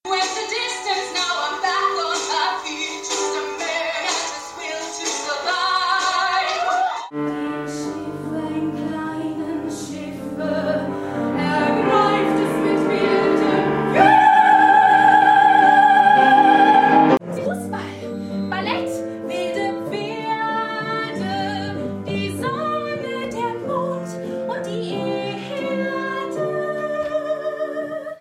Live-Gesang Mix:
Live-Gesang-Mix-1.mp3